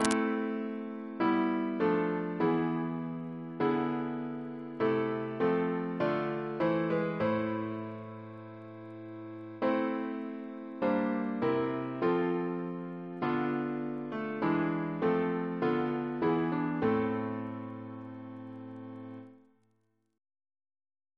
Double chant in A minor Composer: Henry Smart (1813-1879) Reference psalters: ACB: 42; CWP: 13; OCB: 140; PP/SNCB: 79; RSCM: 119